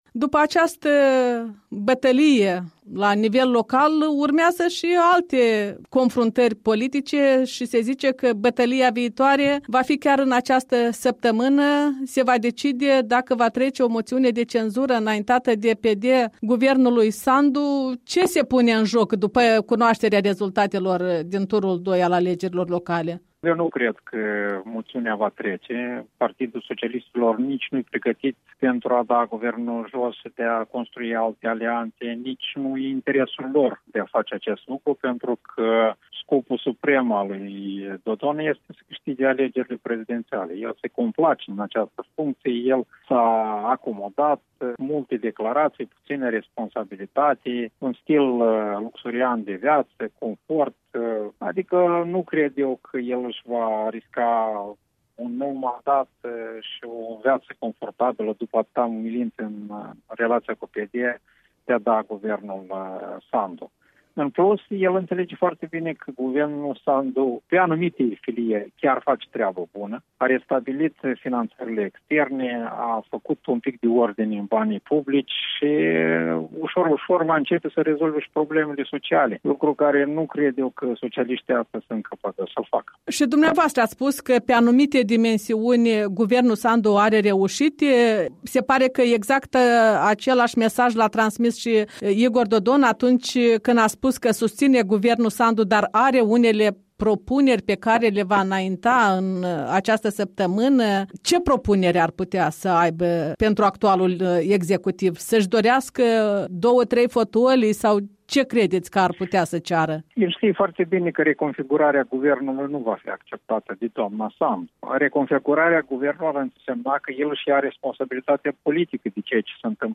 Un interviu cu fostul premier și omul de afaceri Ion Sturza.